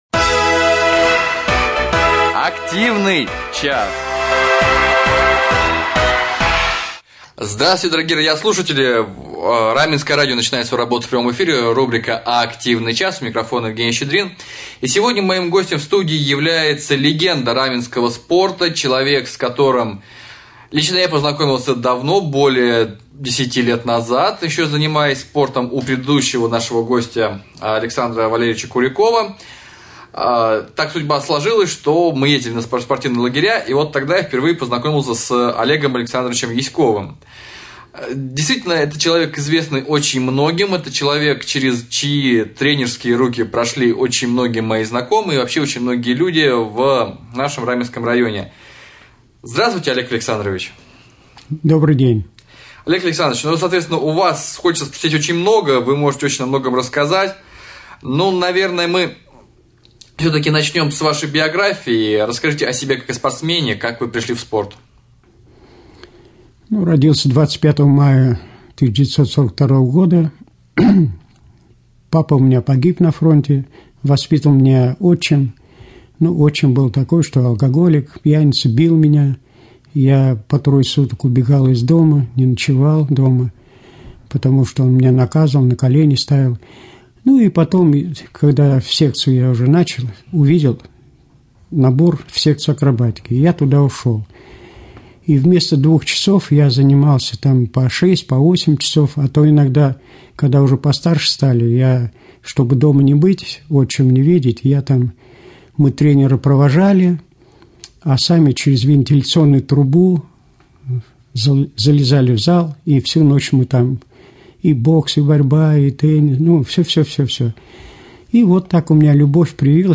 — Как сохранить активность и бодрость до 75 лет? — Действует ли каток для катания в летнее время? — Кто придумал Раменскую лыжероллерную трассу? «Активный час» на Раменском радио.